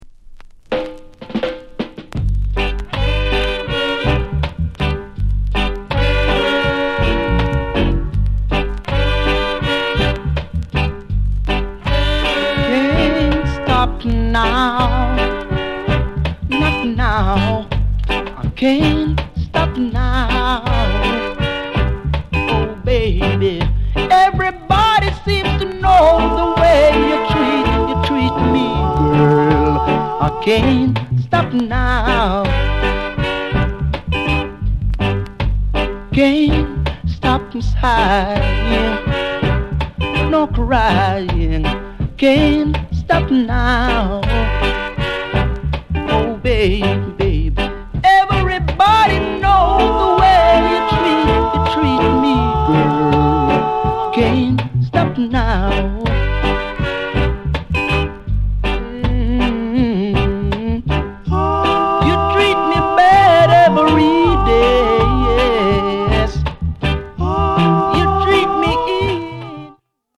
SOUND CONDITION A SIDE VG